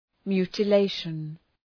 Shkrimi fonetik{,mju:tə’leıʃən}